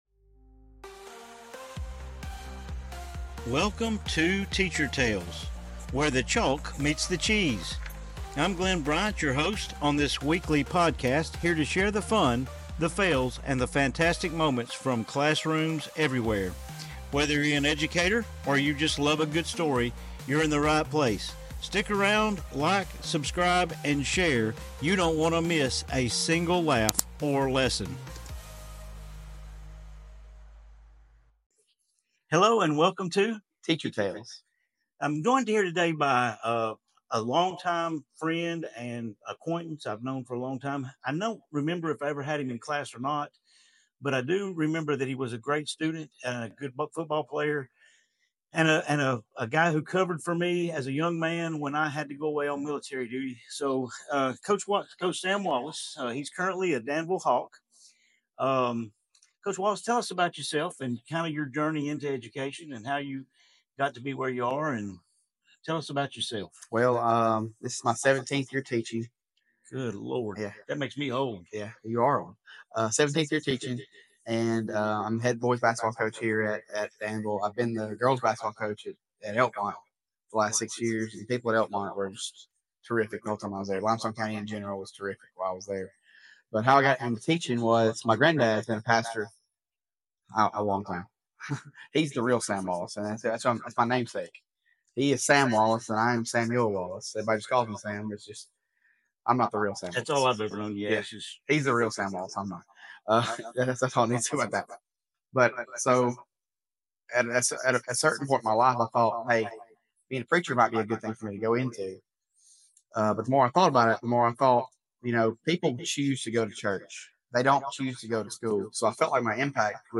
In this episode I interview